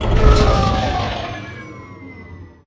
shutdown.wav